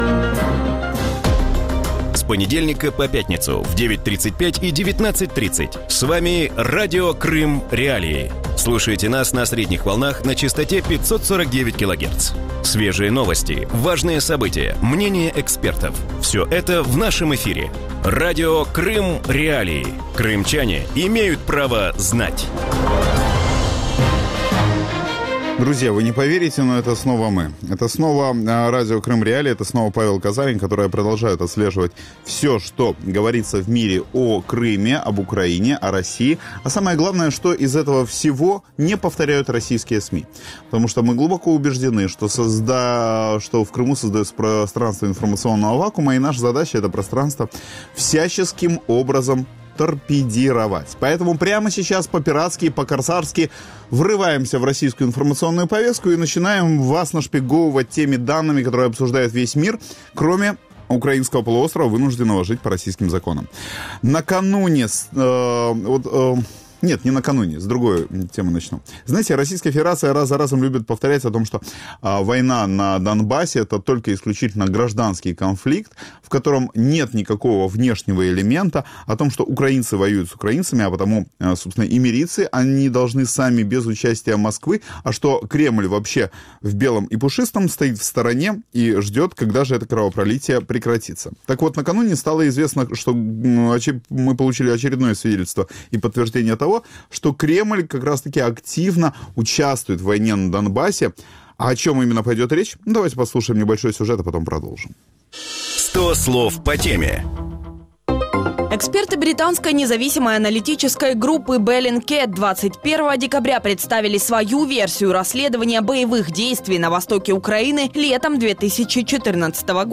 У вечірньому ефірі Радіо Крим.Реалії говорять про новий звіт міжнародної дослідницької групи Bellingcat, у якому йде мова про артобстріли України з російської території. Що нового розповіли військові експерти у своїй доповіді про російську агресію і чи може вона вплинути на політичну ситуацію?